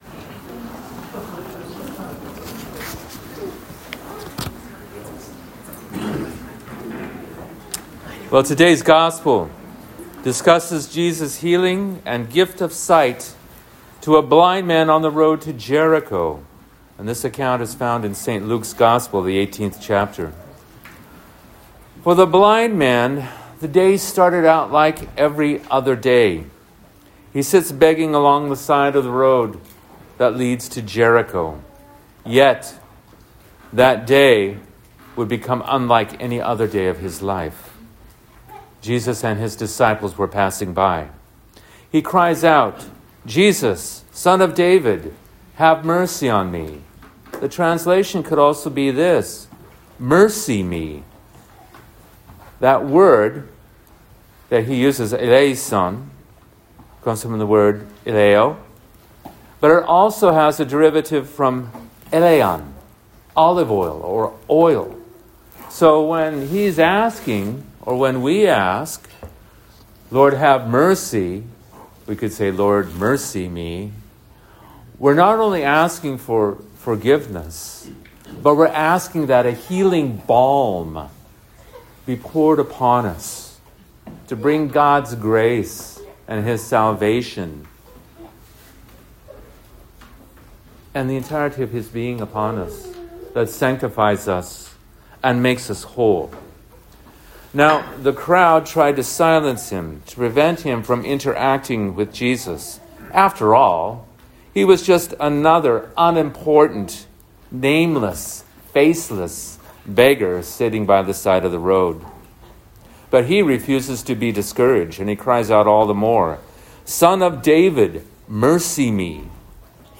The following is a corresponding sermon given January 18, 2026 at Holy Resurrection Orthodox Church, Tacoma, Washington: https